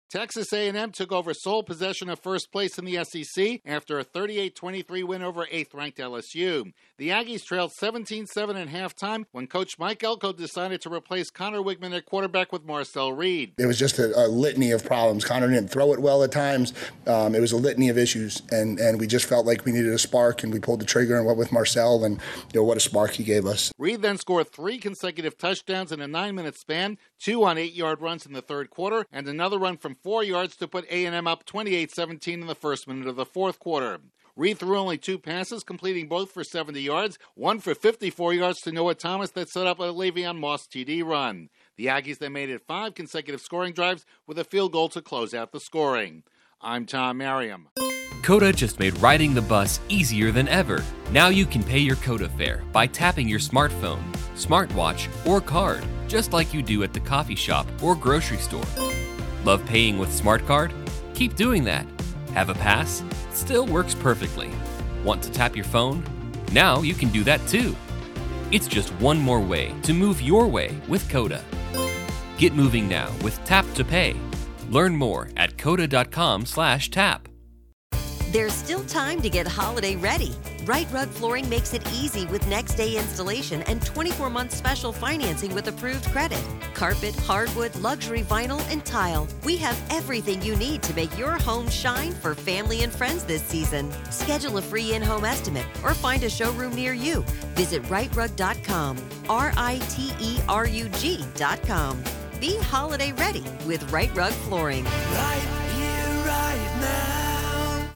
Texas A&M turns on the jets in the second half against LSU. Correspondent